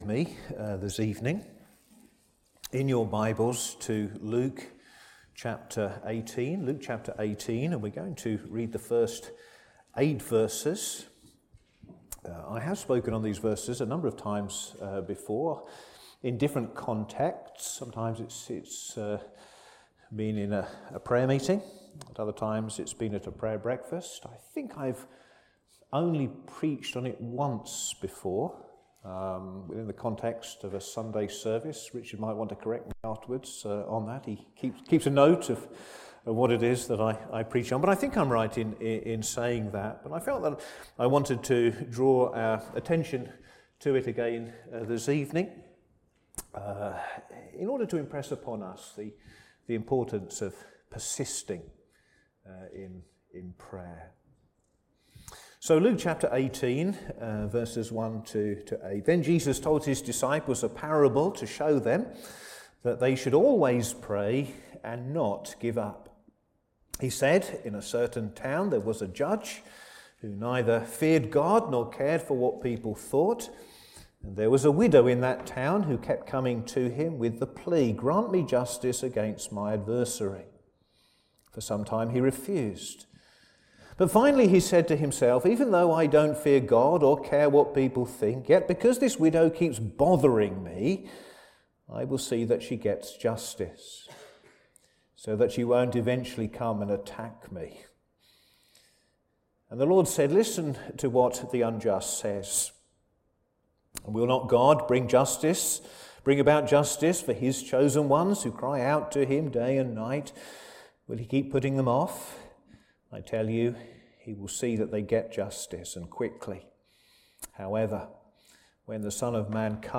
Sermons
Service Morning